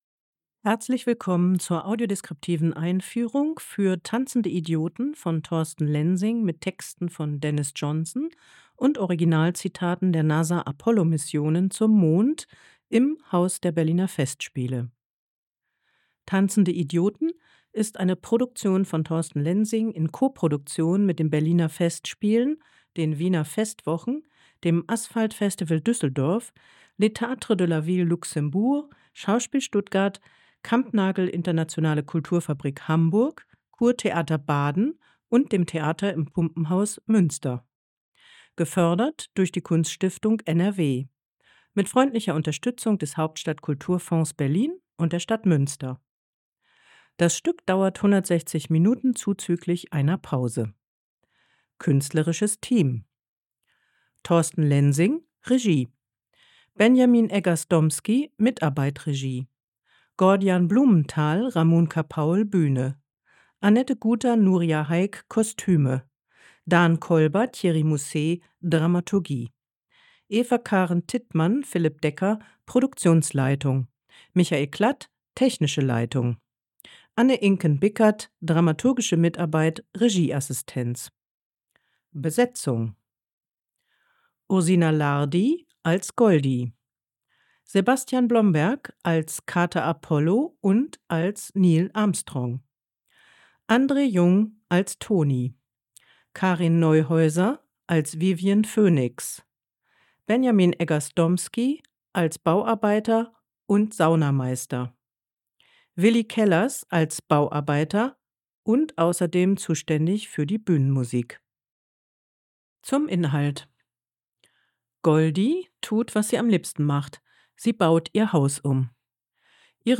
Audiodeskription „Tanzende Idioten”
pas25_audiodeskriptive_einfuehrung_tanzende_idioten.mp3